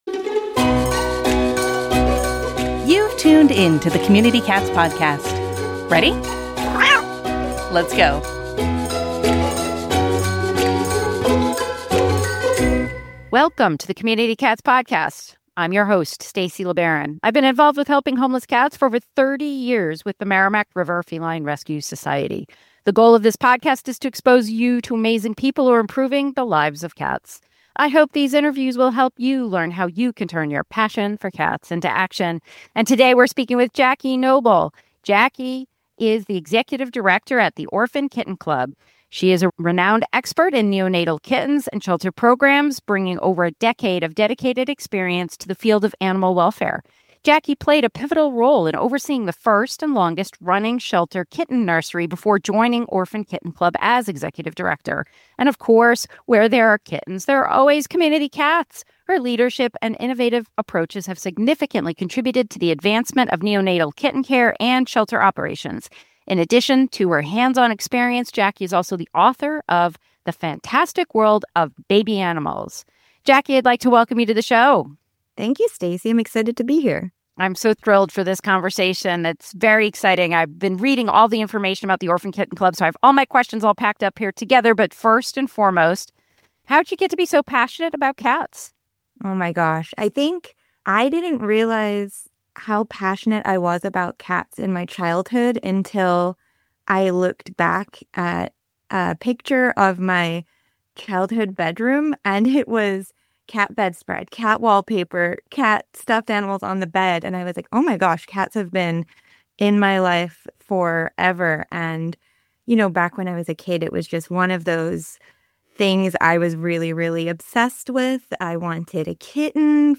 In this lively episode